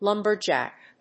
/ˈlʌm.bə.dʒæk(米国英語), ˈlʌmbɜ:ˌdʒæk(英国英語)/
アクセント・音節lúmber・jàck